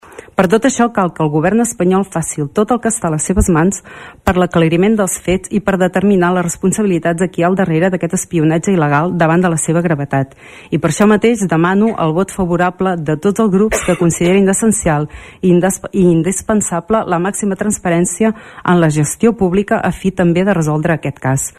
El ple de l‘Ajuntament de Tordera va aprovar una moció a favor de demanar al Congrés Espanyol la investigació del CatalanGate, un “espionatge il·legal de l’Estat contra els líders independentistes” que ha destapat el diari “New Yorker”.
La regidora d’ERC, Marta Paset, demana explicacions al govern espanyol i el “suport democràtic” de la resta de partits.